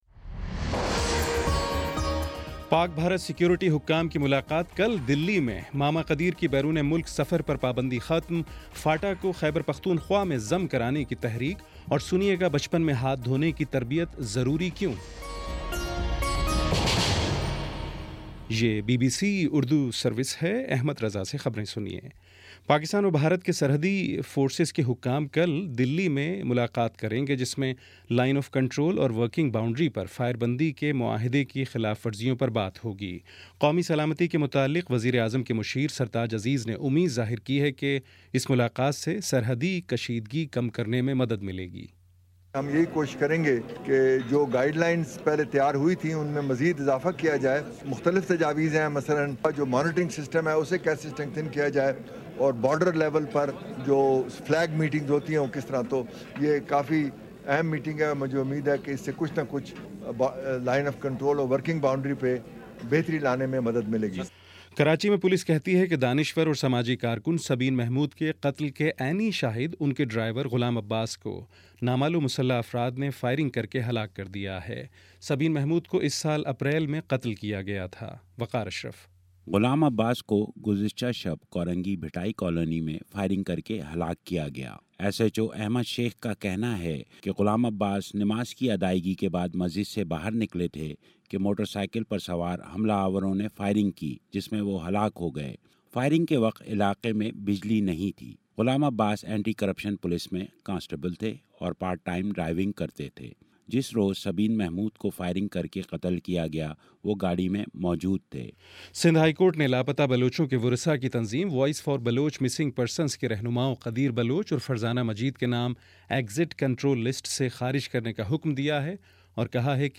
ستمبر8 : شام پانچ بجے کا نیوز بُلیٹن